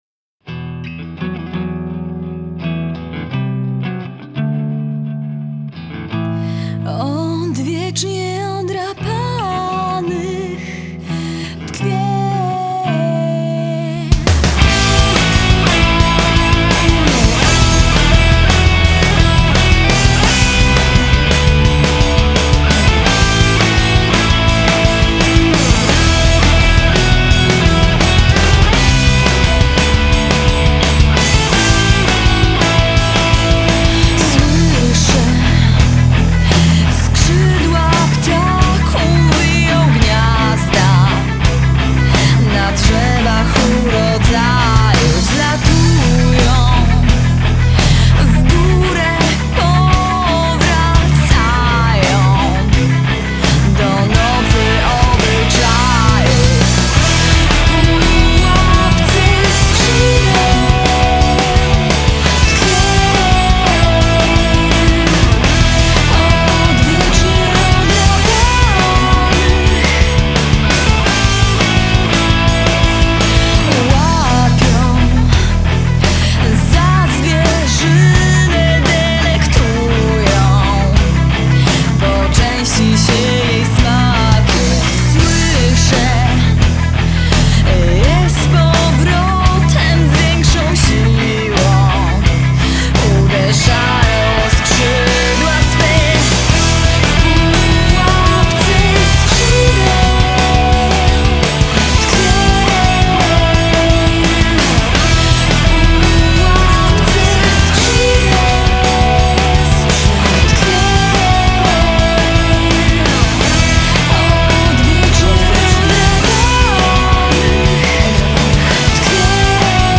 Gatunek: Rock
Vocals
Guitars
Bass
Drums